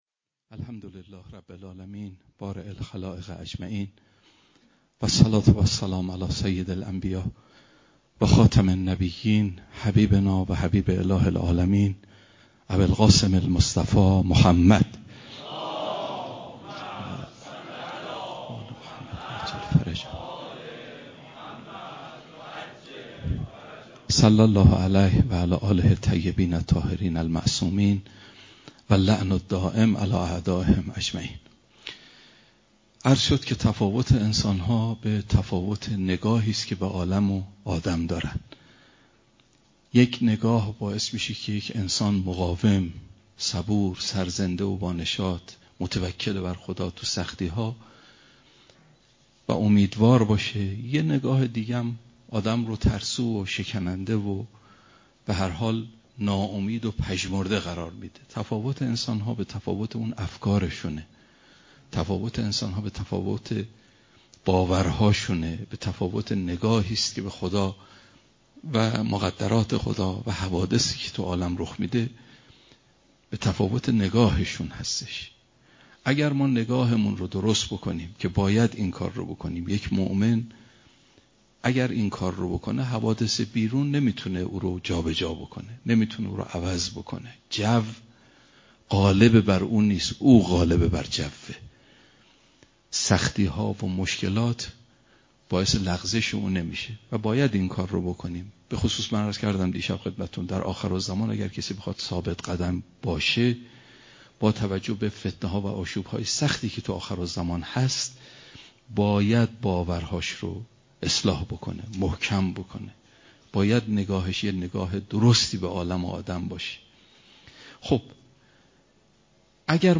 بیانات معرفتی